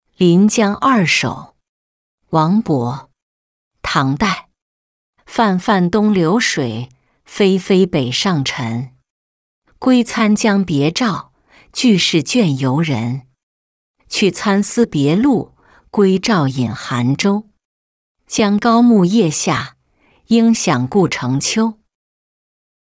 临江二首-音频朗读